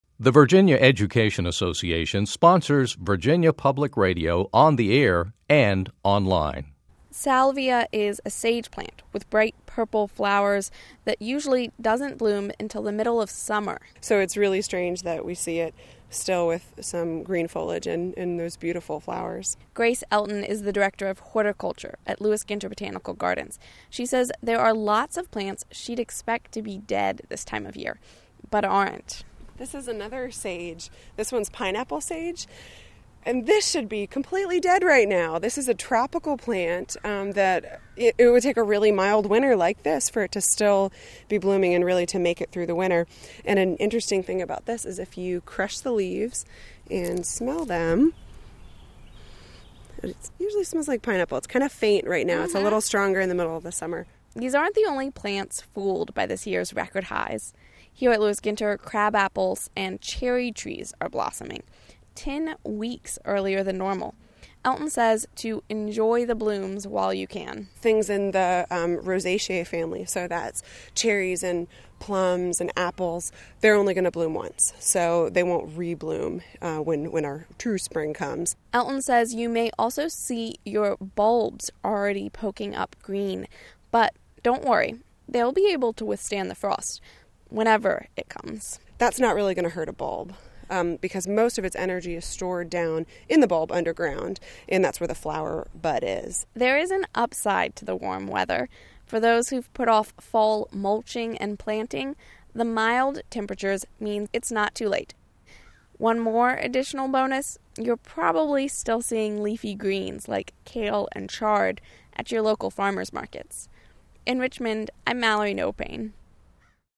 visited Lewis Ginter Botanical Garden in Richmond, to see what’s blooming.